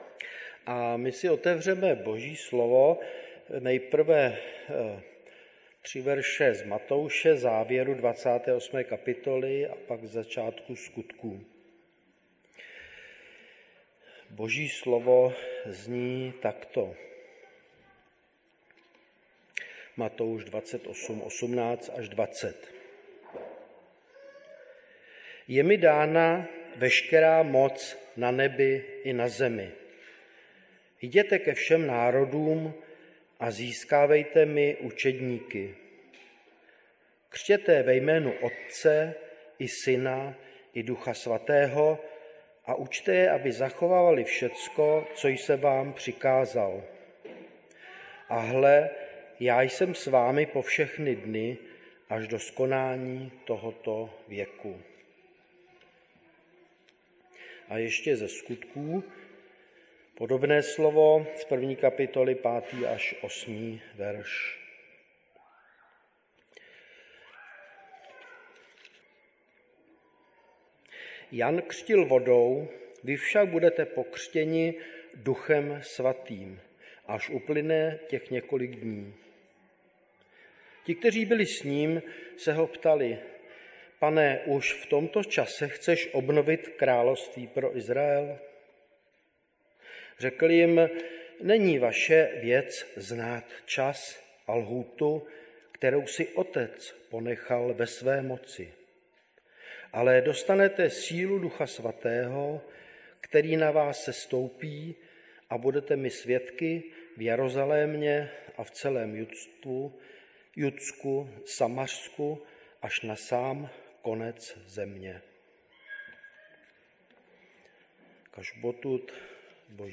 Nedělní bohoslužby Husinec přehrát